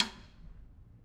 Snare2-taps_v1_rr2_Sum.wav